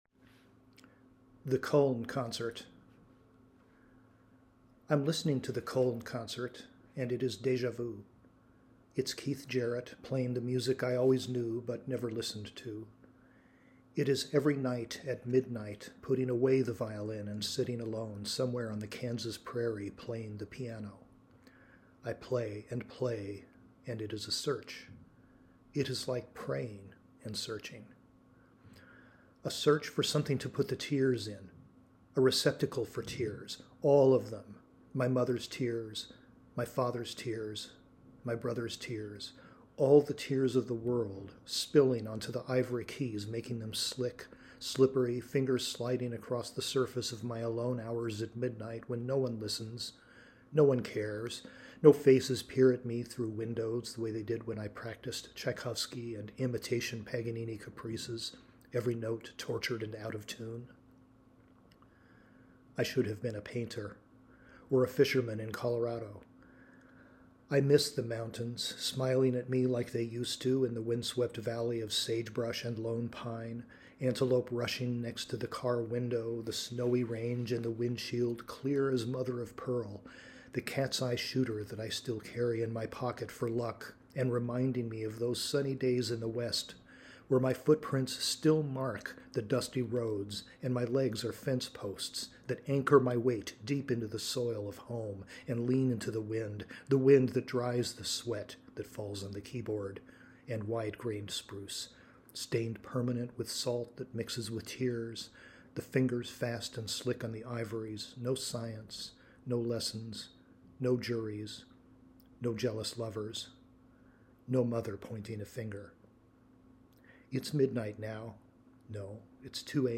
The Sunday Poem  is published weekly, and strives to include the poet reading their work.